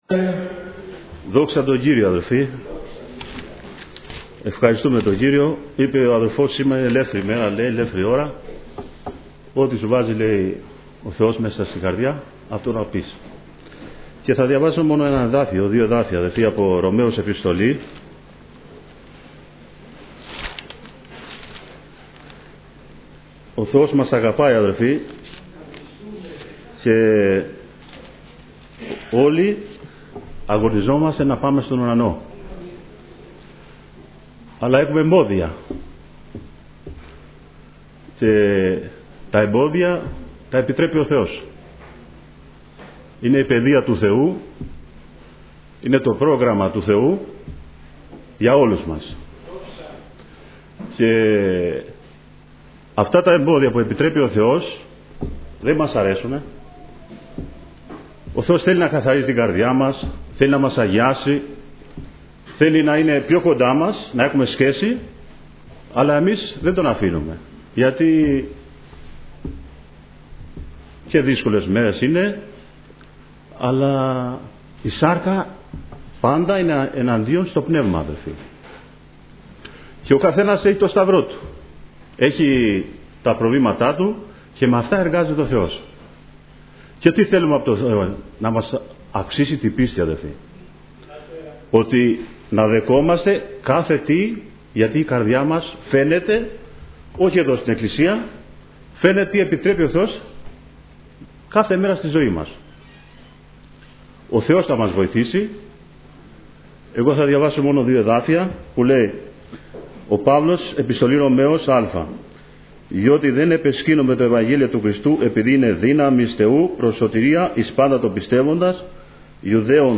Διάφοροι Ομιλητές Ομιλητής: Διάφοροι Ομιλητές Λεπτομέρειες Σειρά: Κηρύγματα Ημερομηνία: Δευτέρα, 01 Μαΐου 2017 Εμφανίσεις: 274 Γραφή: Προς Ρωμαίους 1:16-1:17 Λήψη ήχου Λήψη βίντεο